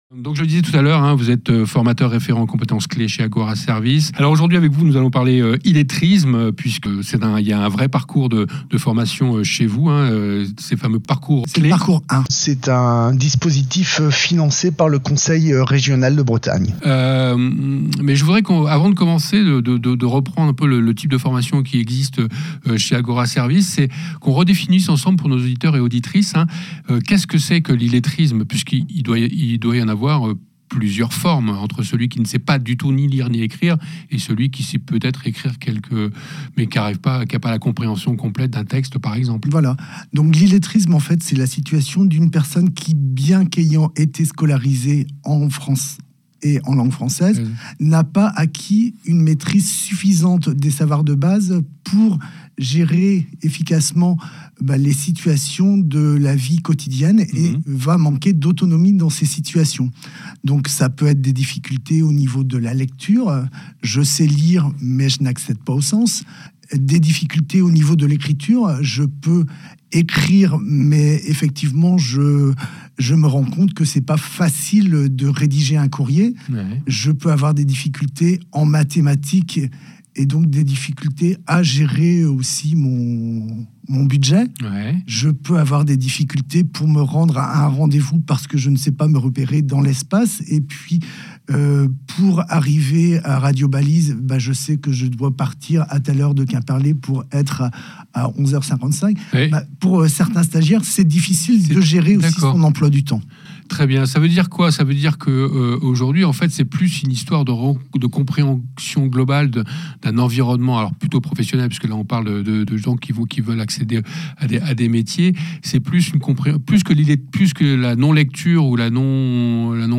Illettrisme Interview